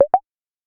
imbee-message-notification-sound.wav